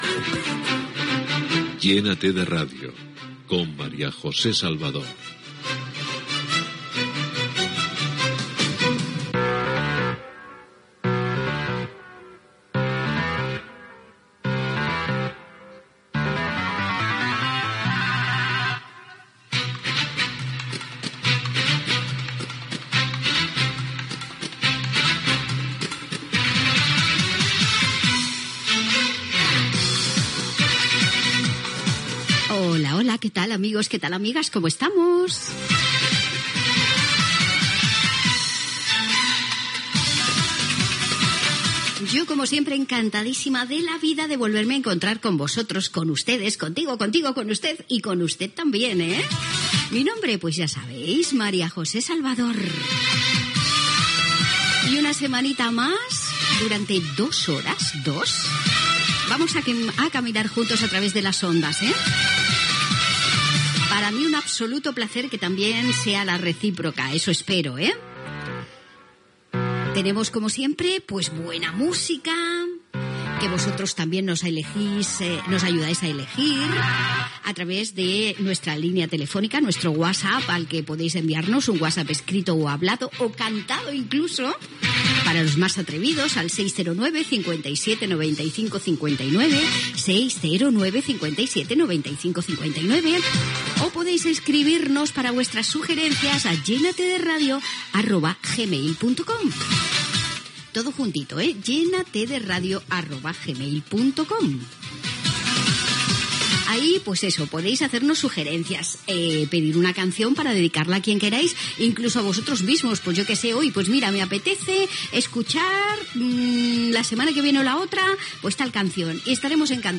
Careta del programa, presentació amb els telèfons del programa i adreça electrònica, pel·lícula "La cripta el último secreto".